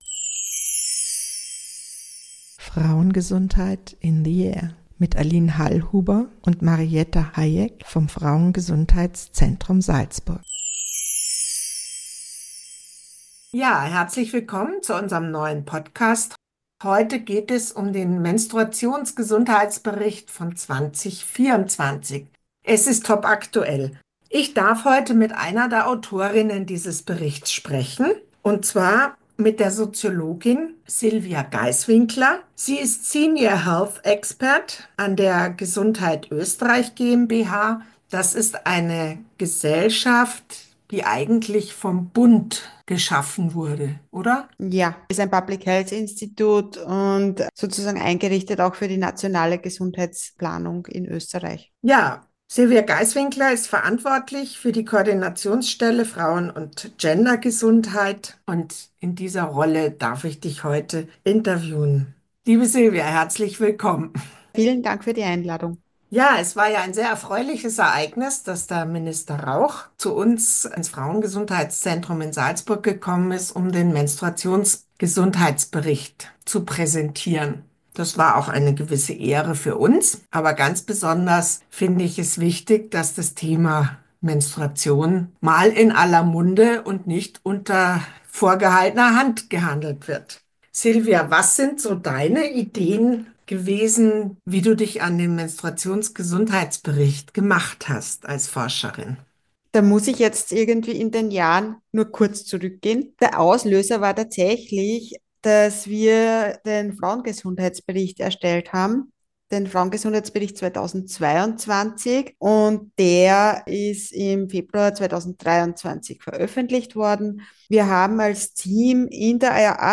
informiert im Gespräch über die Erkenntnisse.